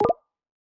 Bamboo Pop v2 Notification1.wav